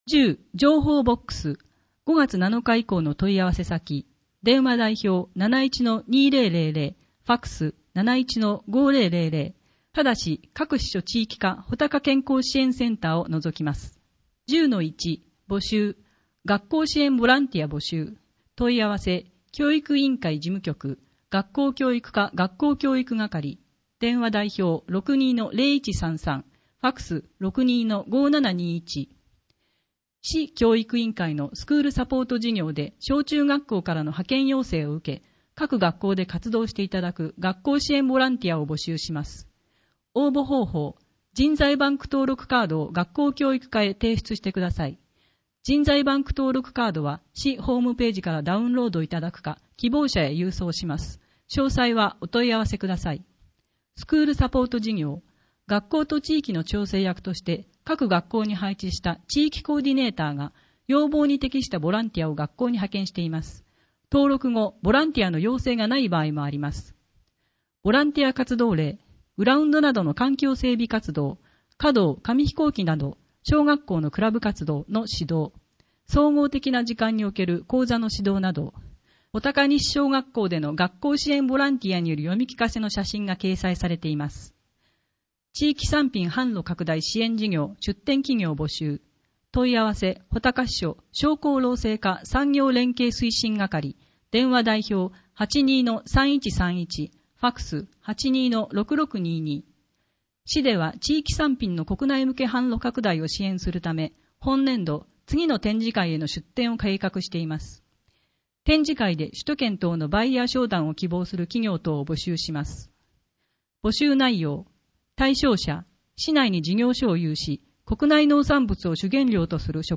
広報あづみの朗読版206号(平成27年4月22日発行) - 安曇野市公式ホームページ
「広報あづみの」を音声でご利用いただけます。この録音図書は、安曇野市中央図書館が制作しています。